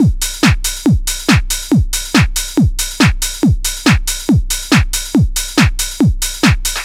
NRG 4 On The Floor 015.wav